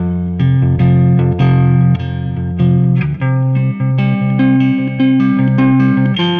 Weathered Guitar 07.wav